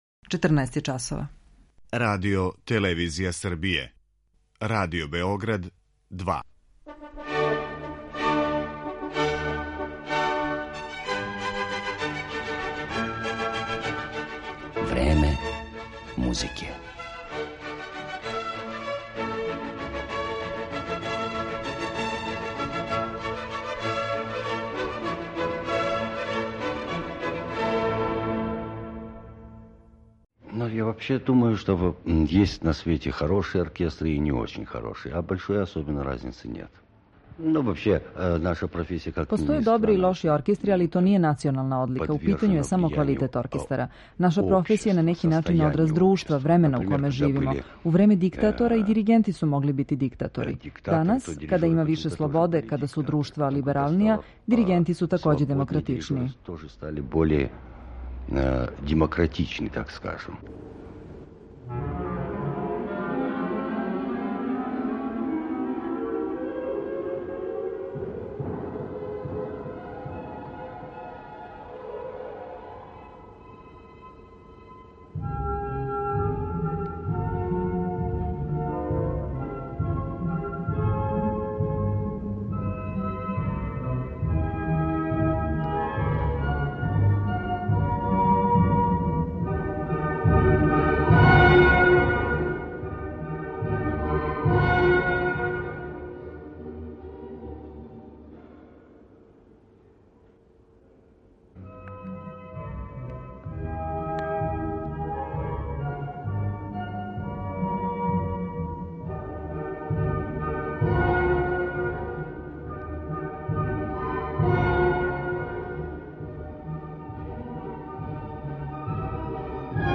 Овог харизматичног уметника, који је дириговао и највећим светским ансамблима, представићемо претежно као тумача руске музике, као и кроз ексклузивни интервју.